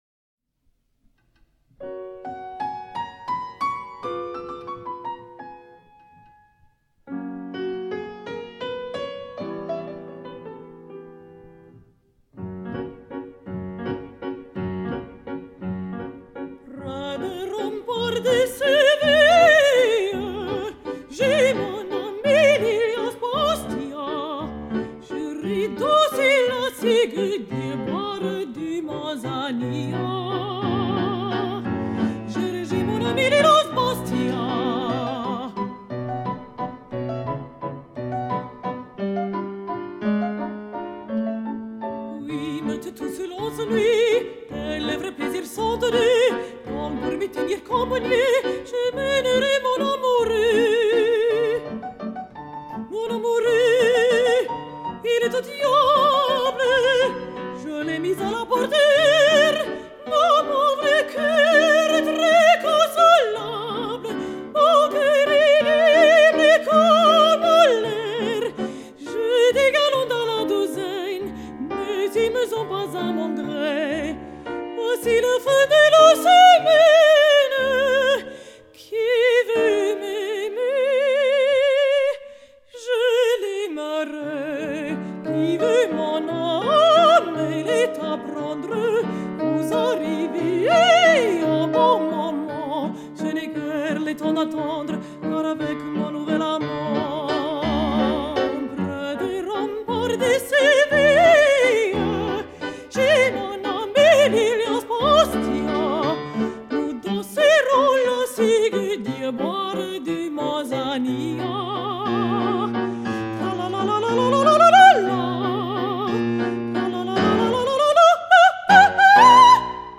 Mezzosopranistin & Gesangspädagogin
Klavier